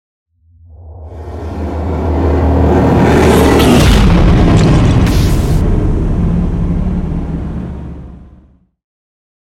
Dramatic electronic whoosh to hit trailer
Sound Effects
Atonal
futuristic
intense
woosh to hit